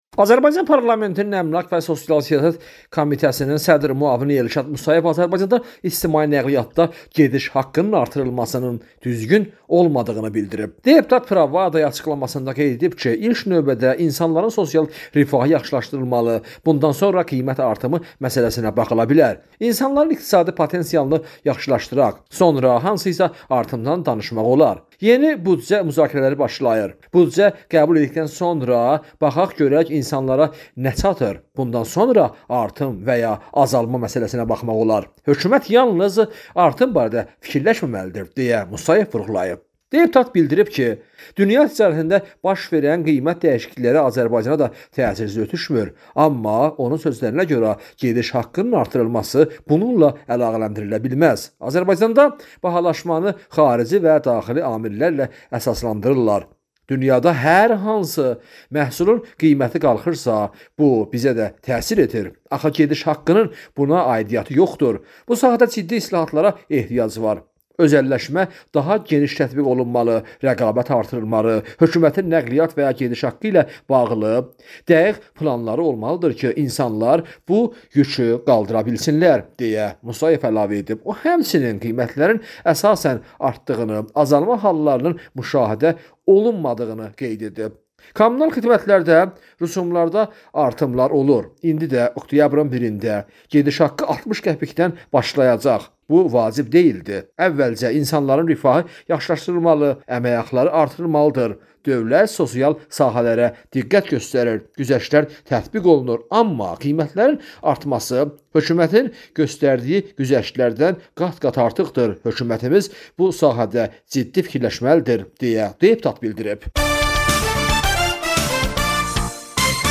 reportajında